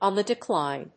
òn the declíne